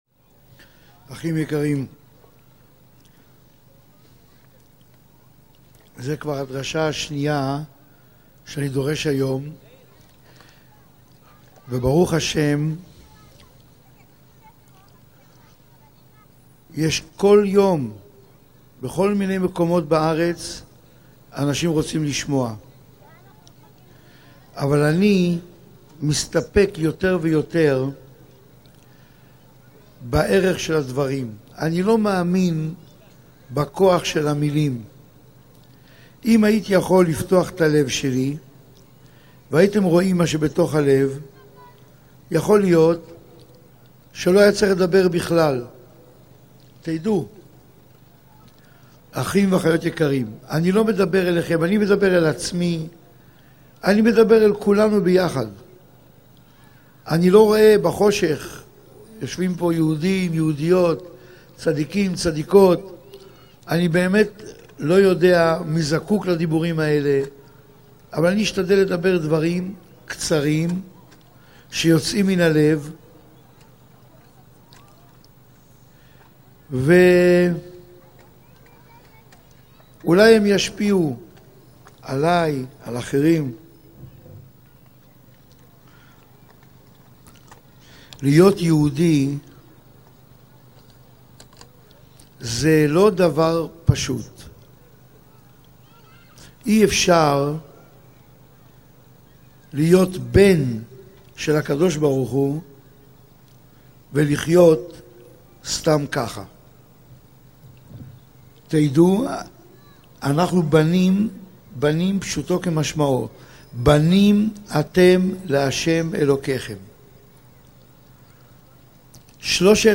שעור תורה לזיכוי הרבים
_הרב אורי זוהר - אם מתים למה חיים, ואם נולדנו למה מתים, מסיבת ראש חודש אלול.mp3